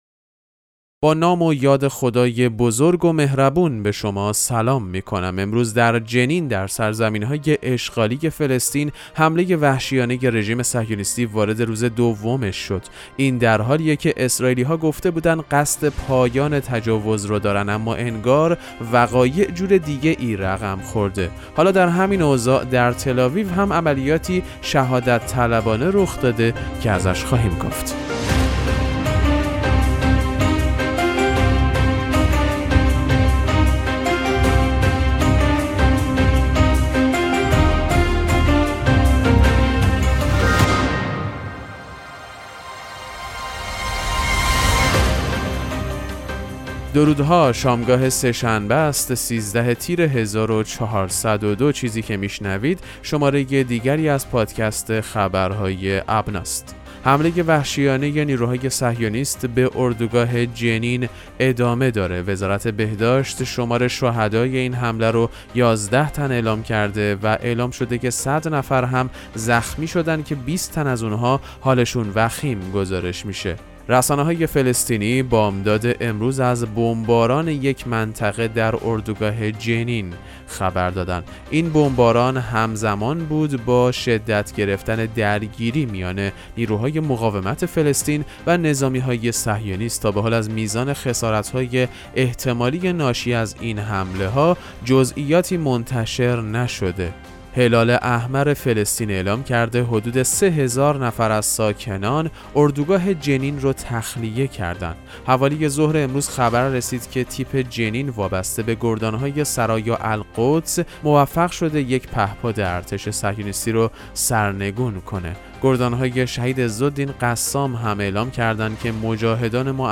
پادکست مهم‌ترین اخبار ابنا فارسی ــ 13 تیر 1402